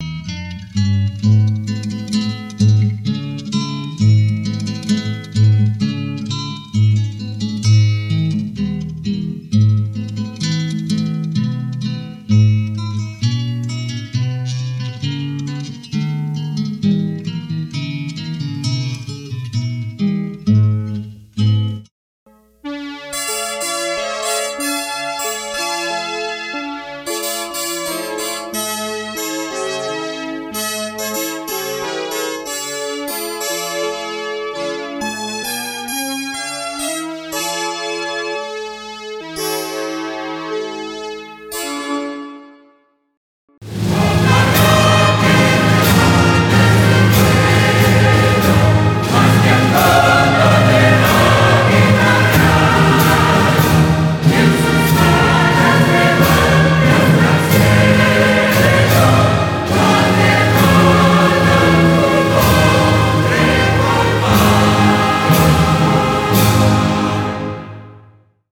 Bolero